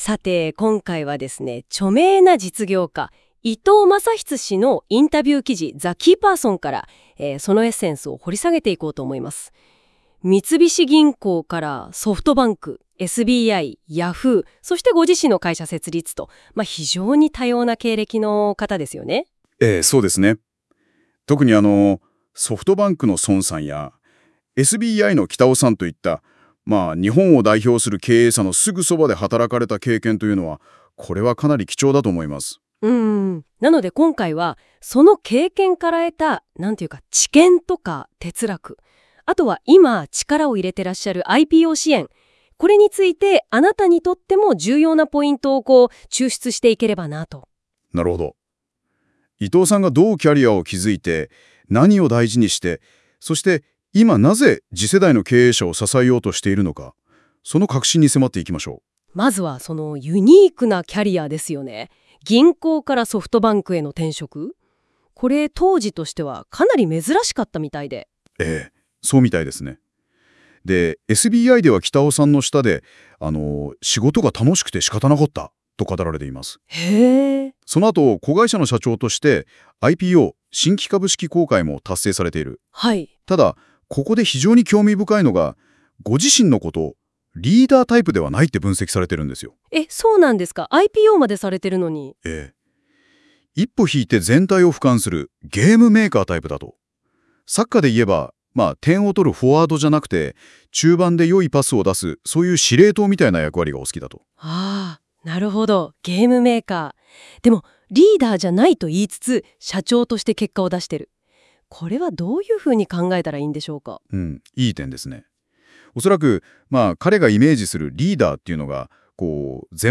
Interviewee